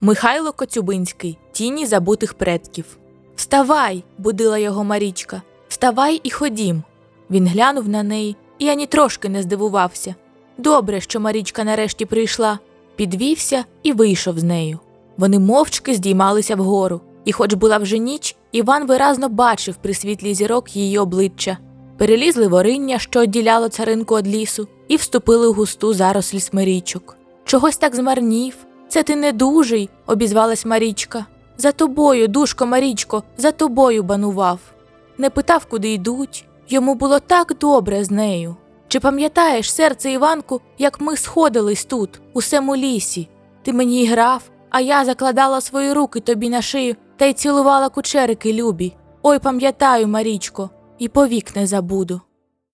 Озвучка Аудіокниги.mp3